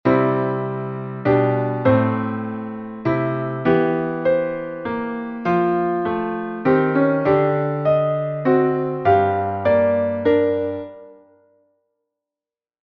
Anticipation [heutige Schreibweise: Antizipation], Vorausnahme; eine Figur, in welcher eine zu einem Basston gegebene und fortschreitende Melodie oder Harmonie ihren Grund und ihre Beziehung erst im nächstfolgenden Basston findet.